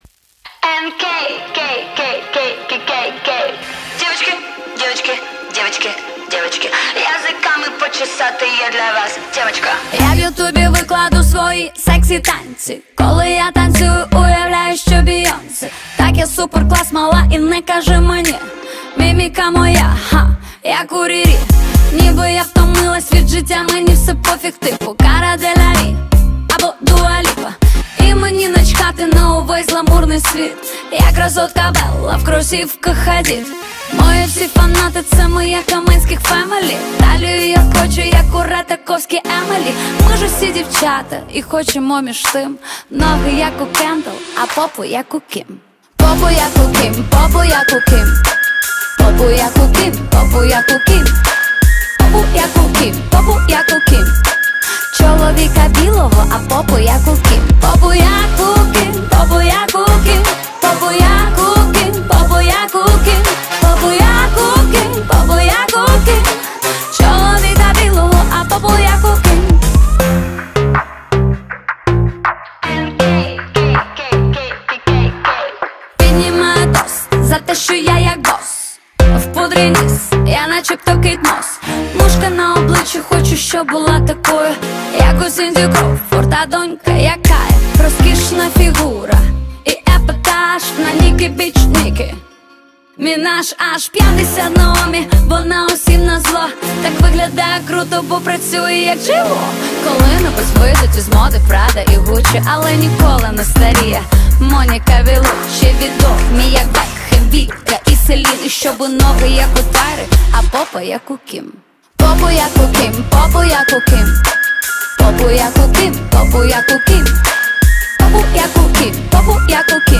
• Жанр:RnB